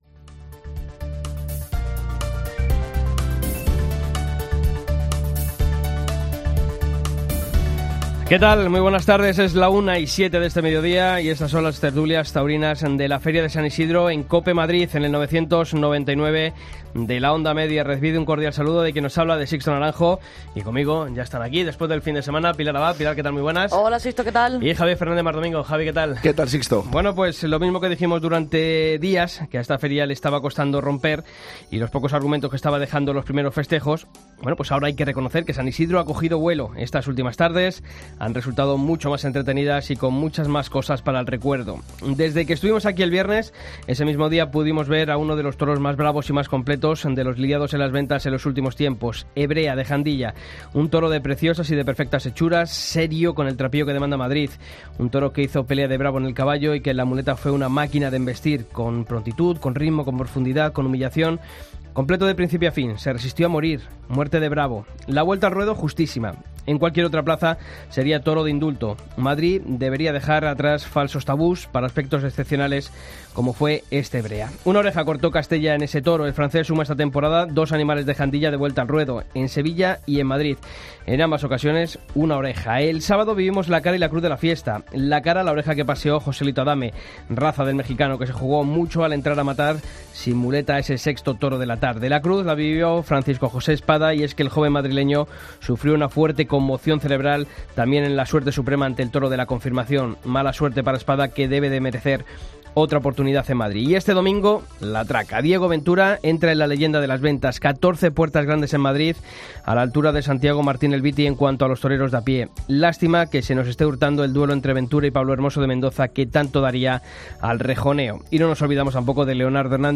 Tertulia de COPE Madrid OM (29/05/17)